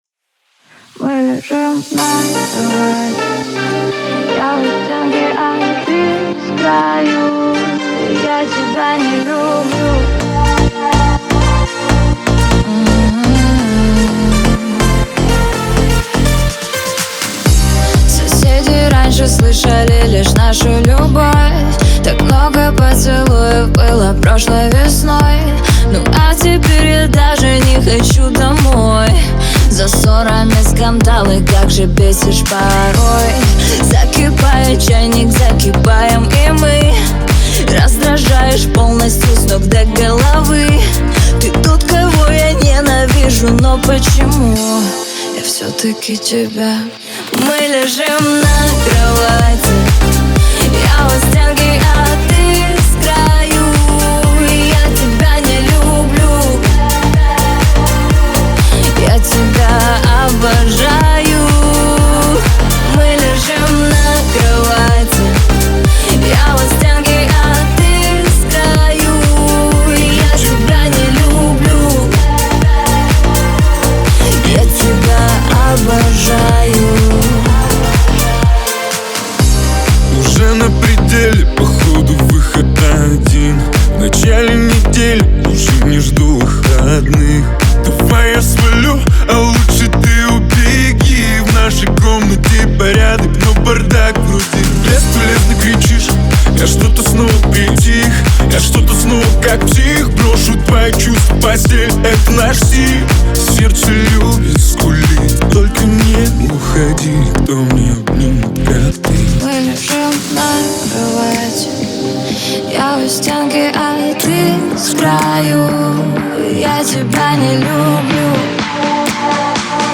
это яркий трек в жанре поп с элементами электронной музыки.